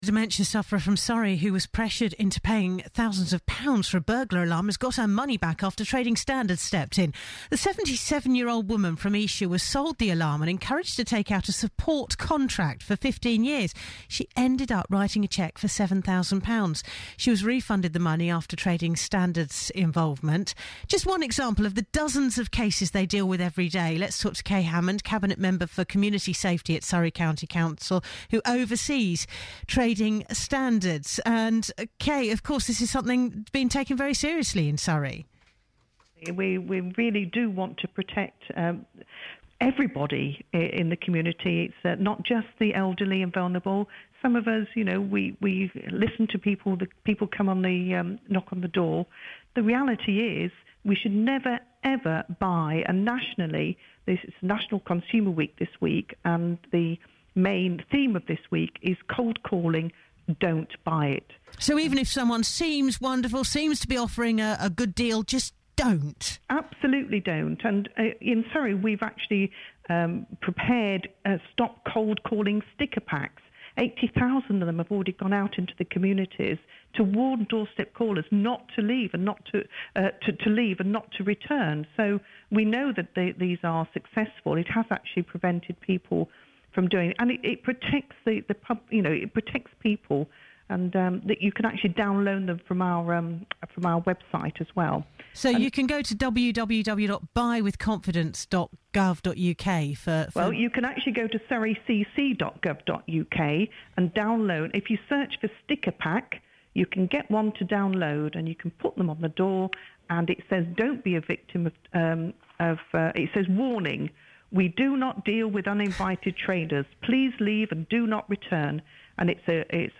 BBC Surrey interview about National Consumer Week